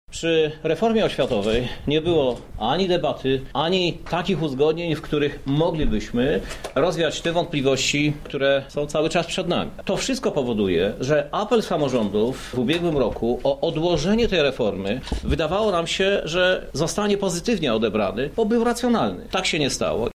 Nie było odpowiedniego dialogu z samorządami – tłumaczy prezydent miasta Krzysztof Żuk: